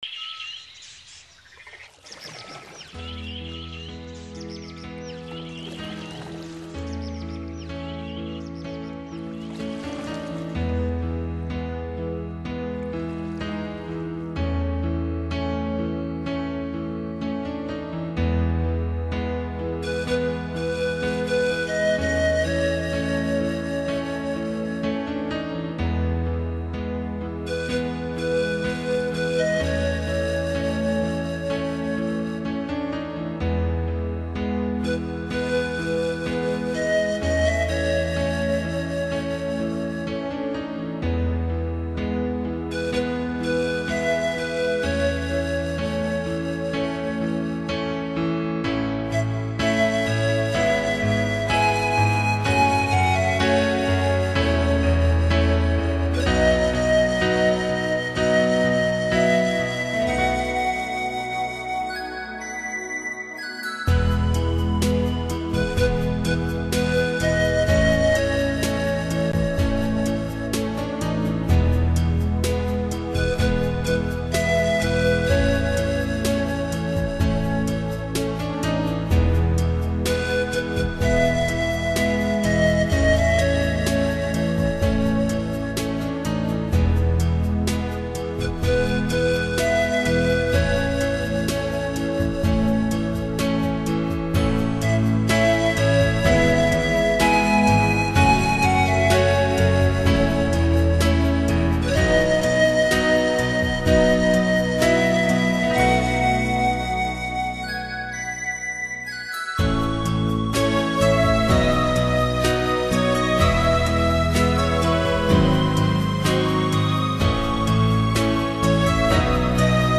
试听曲为低品质wma，下载为320k/mp3